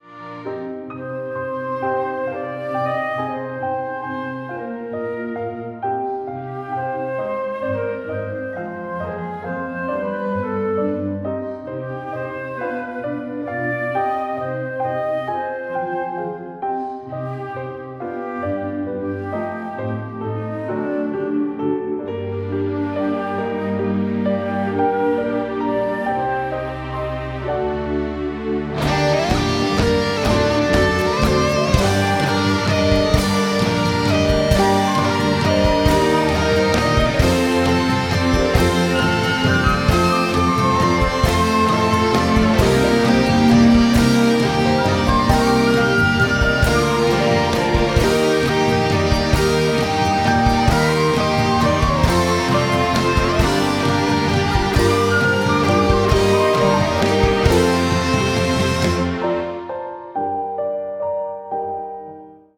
original motion picture soundtrack